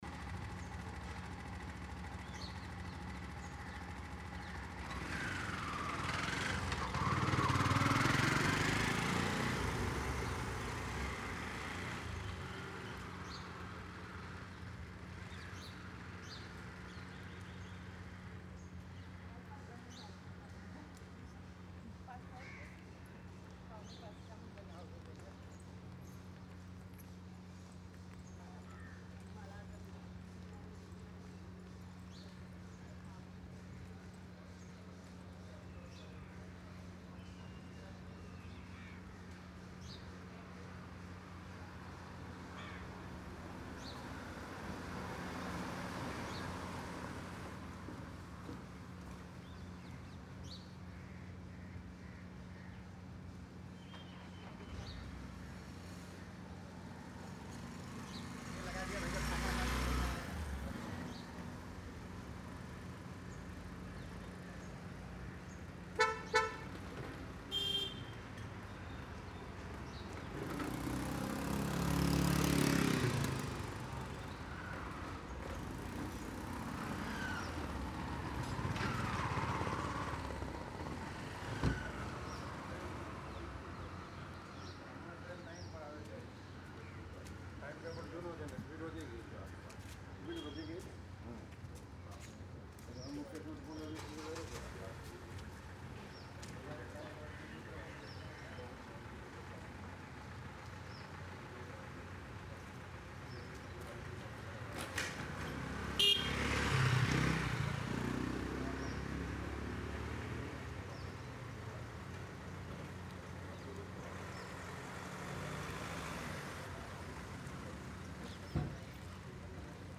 Vehicle Pass-AMB-051
Auto and Bike Pass ambience captures the fast, natural movement of two-wheelers and rickshaws driving past the microphone in an open street environment. Featuring smooth whooshes, engine hums, light acceleration, tire friction, and natural street air movement, this sound adds realistic traffic motion without overwhelming the scene.
Cleanly recorded so it blends smoothly behind dialogue, visuals, or narrative storytelling without distracting attention.
Vehicles / Traffic
Auto + Bike Passing
Outdoor Street Field
Fiftyone-bike-auto-pass.mp3